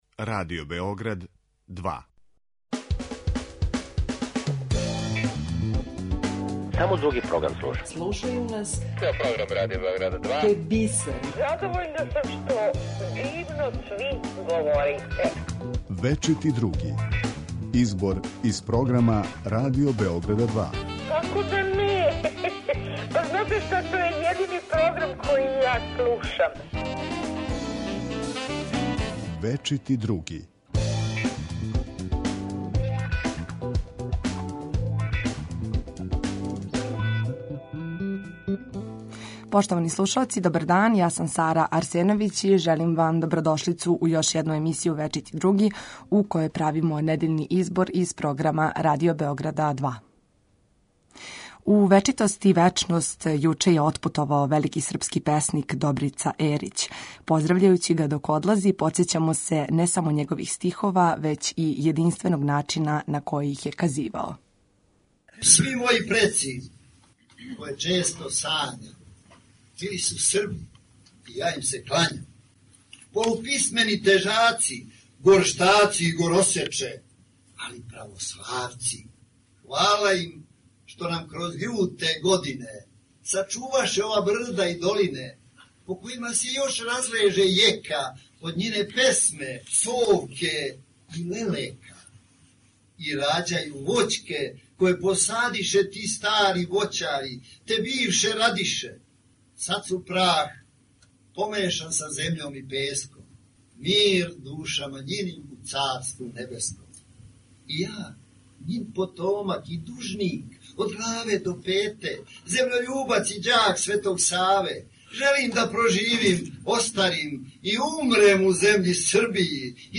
Слушаћемо и неколико минута документарне репортаже